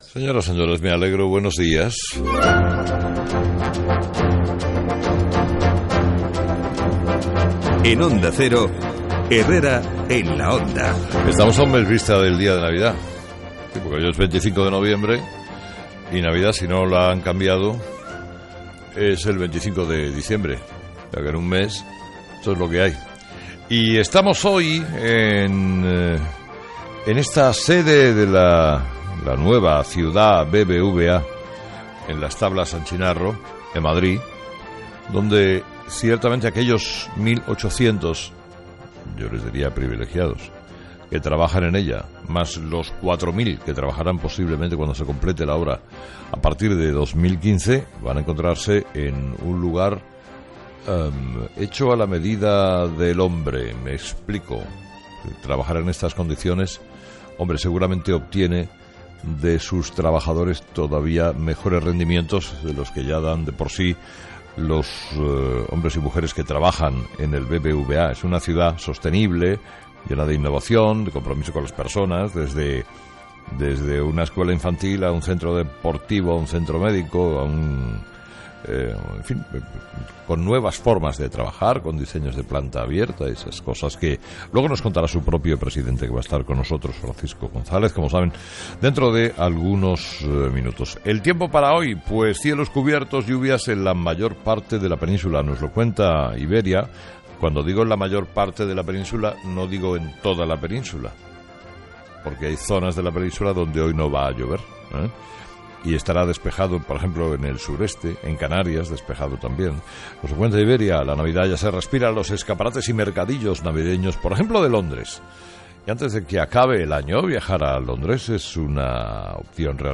25/11/2014 Editorial de Carlos Herrera: "Menuda alegría debe tener Zapatero con Pedro Sánchez"